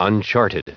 Prononciation du mot uncharted en anglais (fichier audio)
Prononciation du mot : uncharted